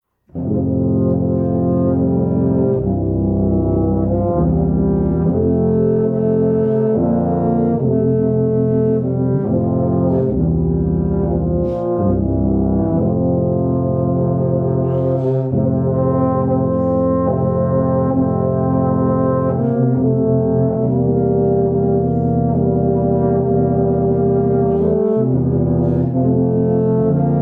An easy tuba quartet arrangement